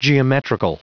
Prononciation du mot geometrical en anglais (fichier audio)
Prononciation du mot : geometrical